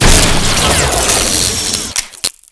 Index of /cstrike/sound/turret